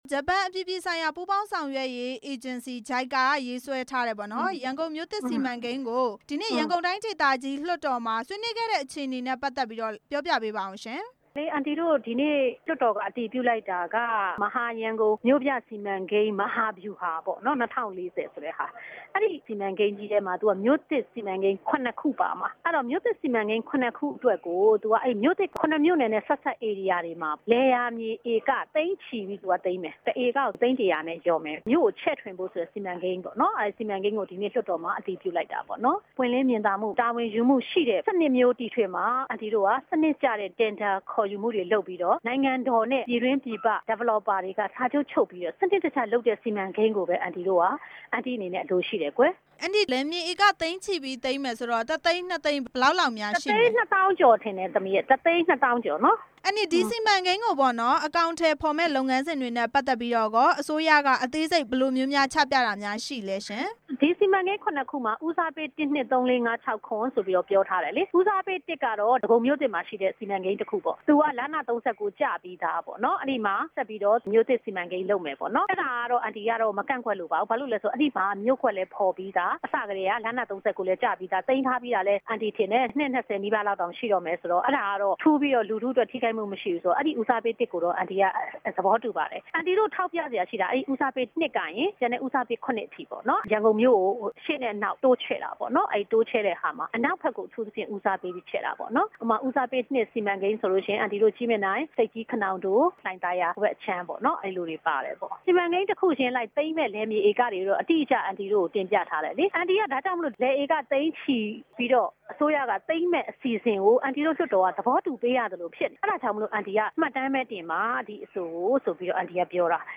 ဒေါက်တာညိုညိုသင်းနဲ့ မေးမြန်းချက်